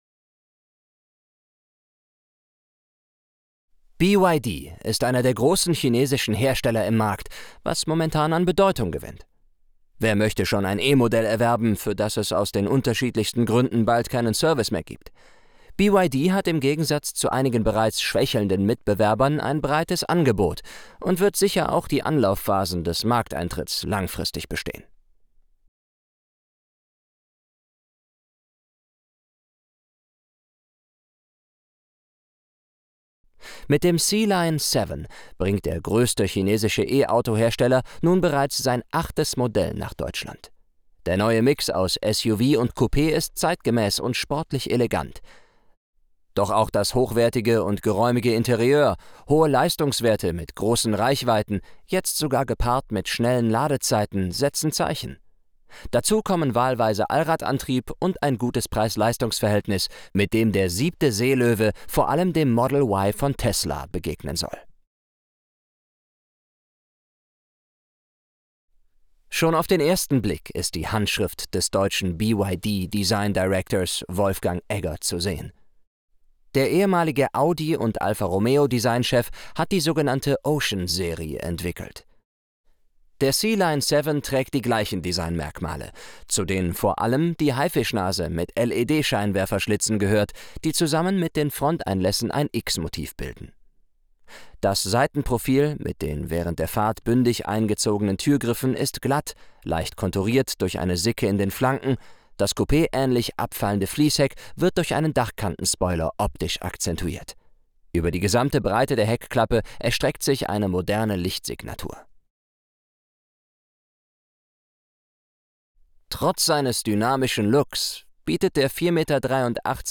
Downloads Deutsch (1080p H.264) Deutsch (720p H.264) Deutsch (360p H.264) Deutsch (Voiceover WAV) Atmo/Clean (1080p H.264) Atmo/Clean (720p H.264) Atmo/Clean (360p H.264)
byd_sealion_24_de_vo.wav